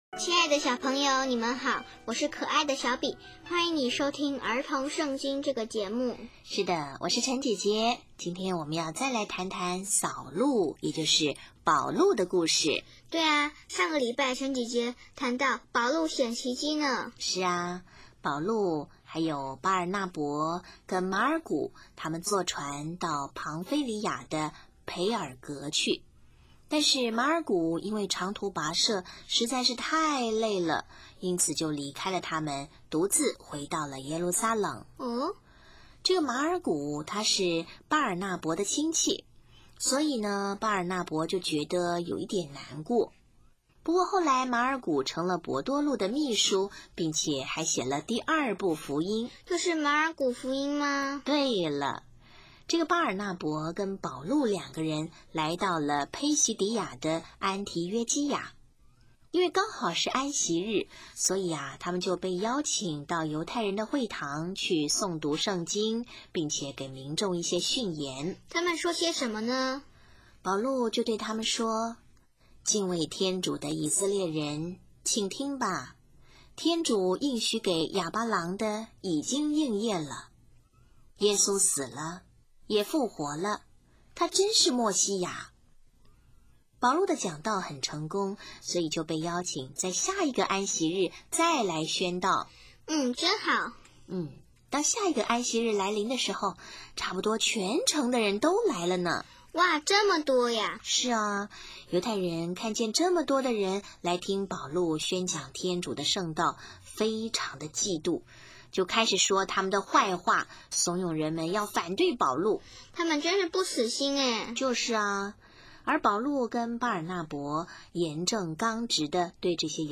【儿童圣经故事】